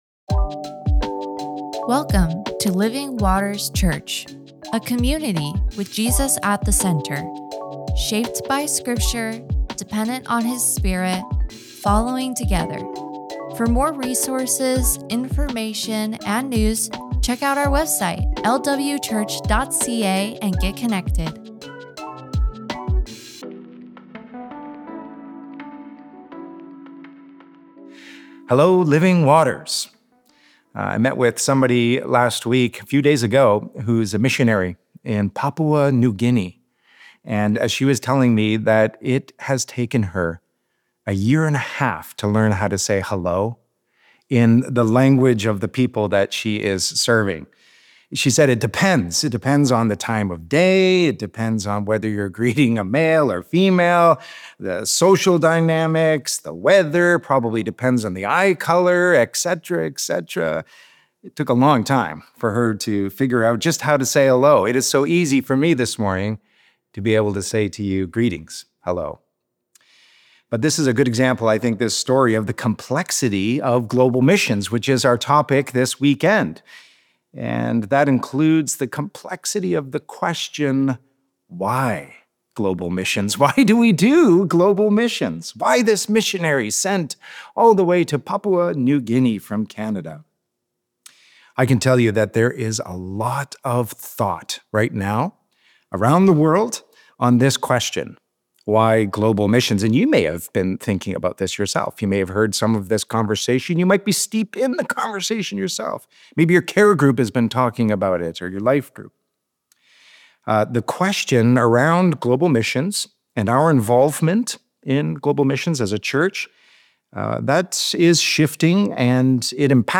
Global Work Current Sermon Why Global?
Guest Speaker